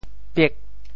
How to say the words 別 in Teochew？